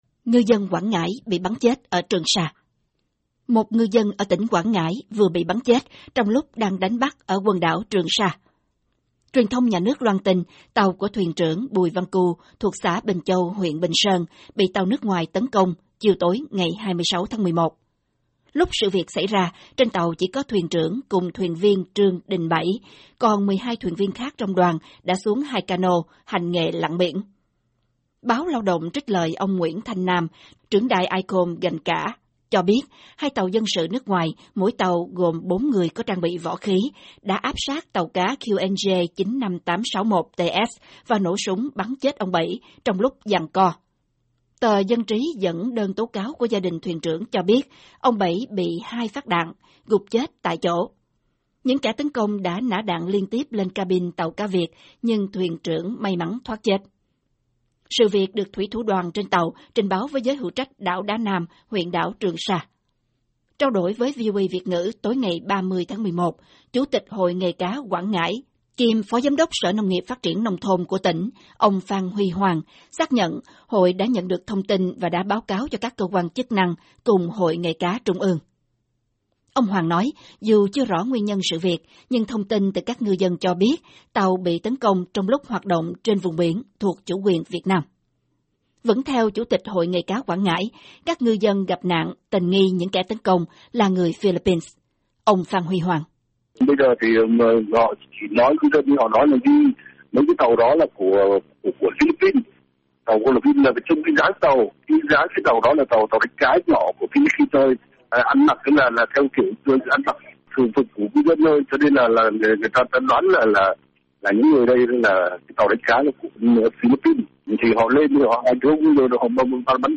Trao đổi với VOA Việt ngữ tối ngày 30/11, Chủ tịch Hội nghề cá Quảng Ngãi kiêm Phó Giám đốc Sở Nông nghiệp-Phát triển Nông thôn của tỉnh, ông Phan Huy Hoàng, xác nhận Hội đã nhận được thông tin và đã báo cáo cho các cơ quan chức năng cùng Hội Nghề cá trung ương.